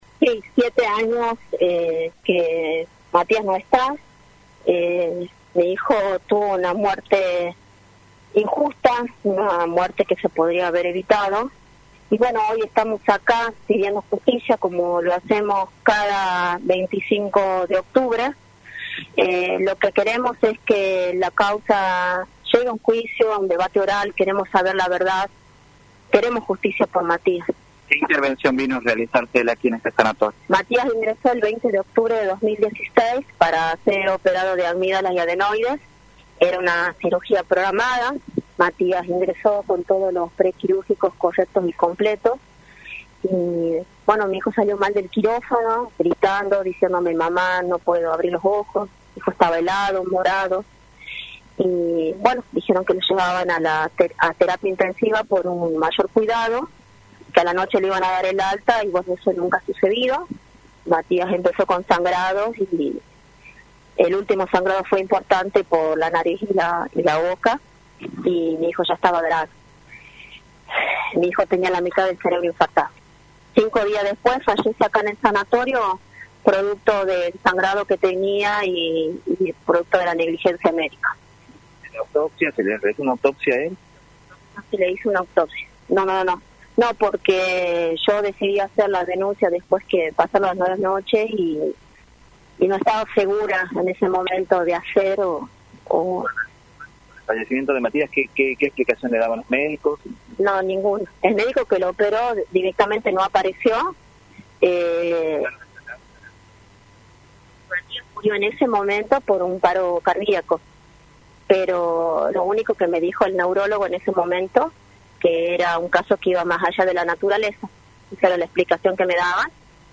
encabezó una manifestación en memoria de su hijo y remarcó en Radio del Plata Tucumán
entrevista